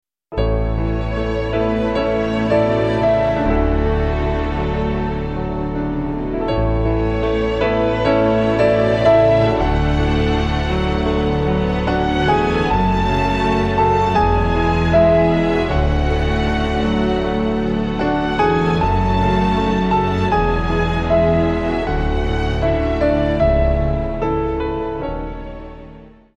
2. Modifier légèrement la mélodie :